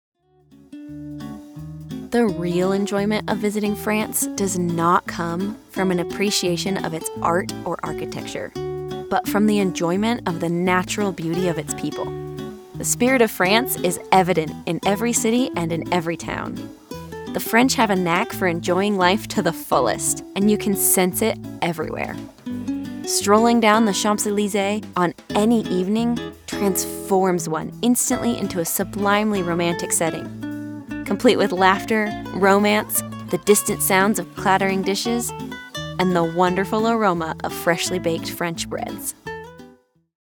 France Travel - Video Narration Demo
I do voiceover full time from a professionally treated in-home studio using a Sennheiser MKH 416 microphone and Apollo Twin X Duo interface.
I was born just outside Seattle Washington, which means I offer that neutral accent that can be used nation wide.
Friendly, conversational, girl next door